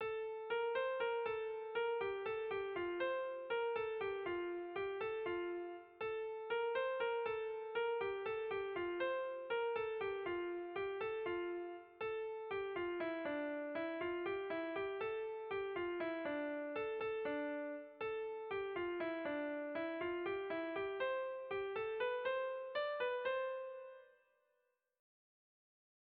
Dantzakoa
Zortziko handia (hg) / Lau puntuko handia (ip)
A-A-B-B